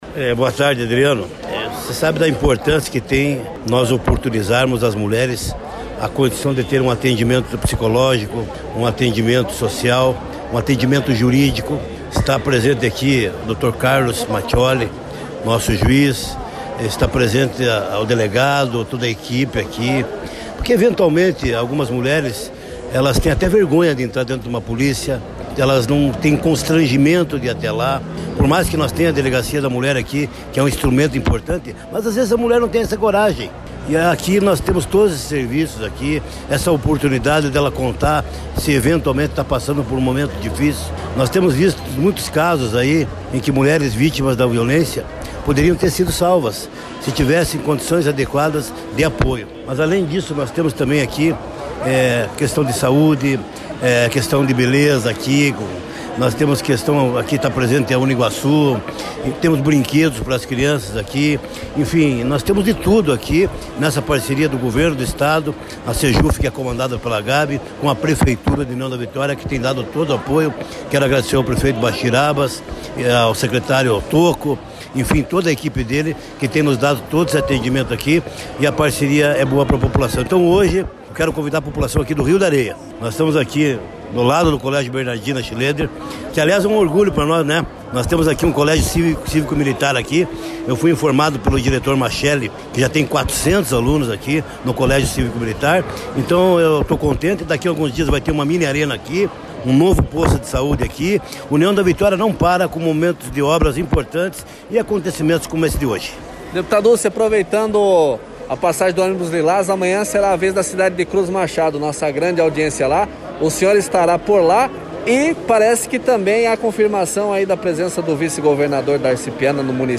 O Deputado Estadual e Líder do Governo no Paraná, Hussein Bakri, destacou a presença do Ônibus Lilás em União da Vitória nesta quinta-feira, e fez um convite às mulheres de Cruz Machado para participarem das atividades nesta sexta-feira no município.